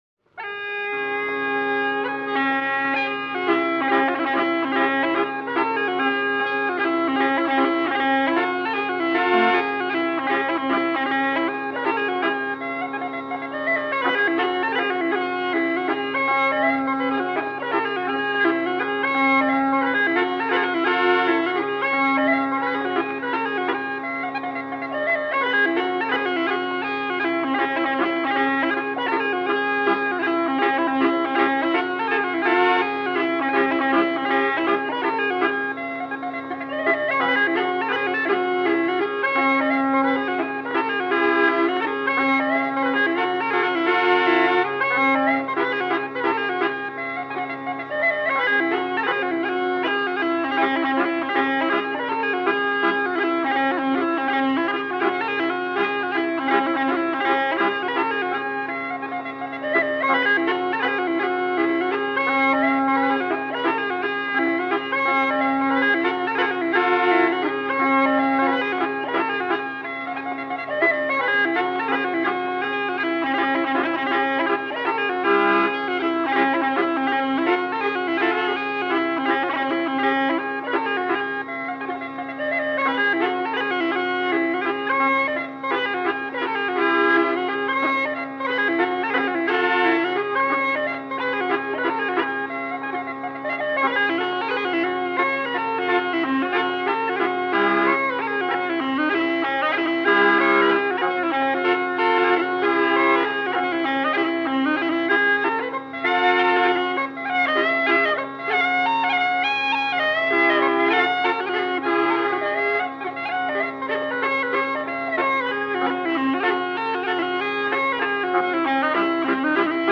Irish Pub Music 1940 - 1950